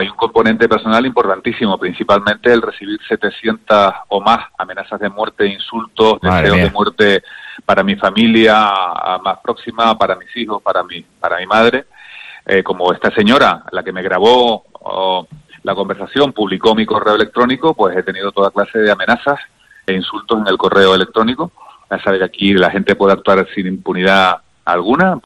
Guillermo Díaz Guerra admite los motivos de su renuncia al área de Bienestar Animal
La noticia, adelantada por Diario de Avisos, ha sido confirmada este viernes por el propio edil en los micrófonos de COPE.